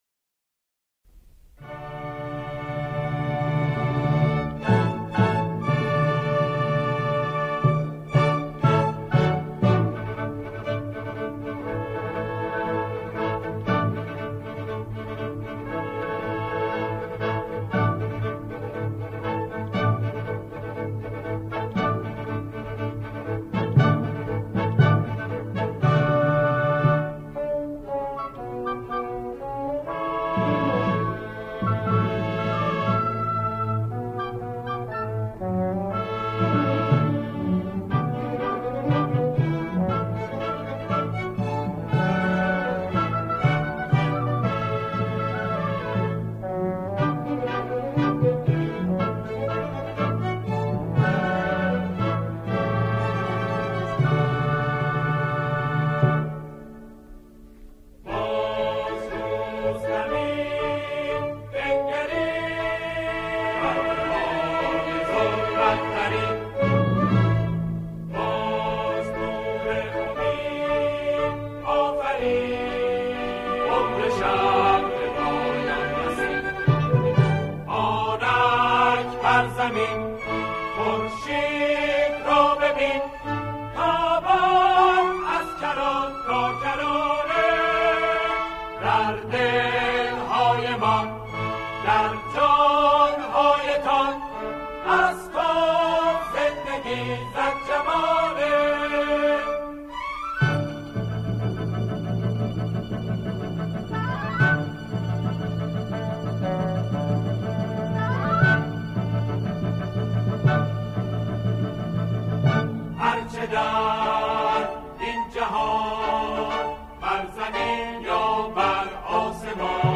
آنها در این قطعه، شعری انگیزشی را همخوانی می‌کنند.